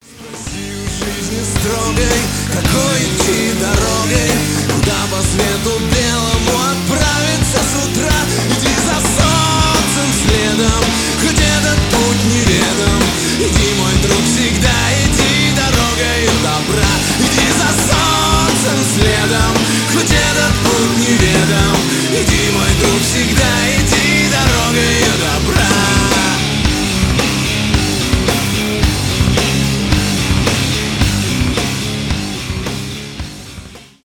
grunge
рок